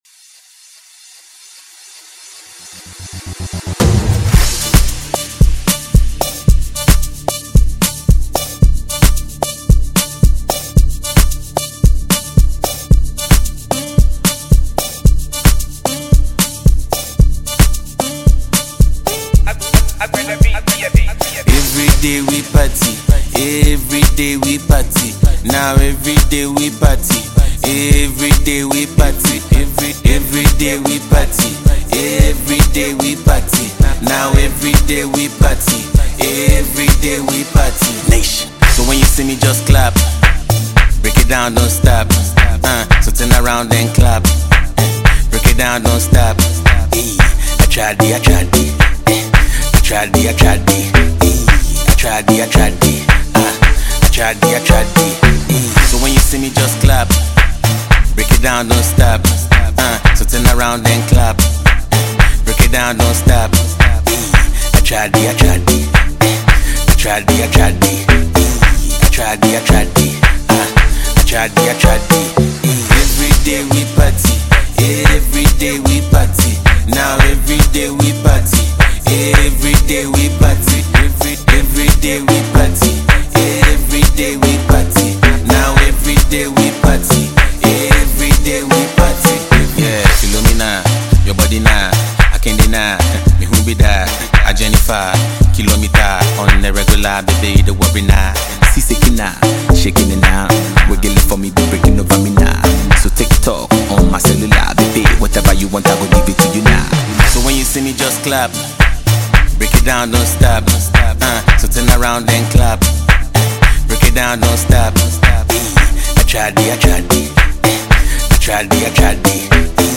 Ghana MusicMusic
Award-winning Ghanaian music duo